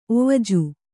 ♪ ovaju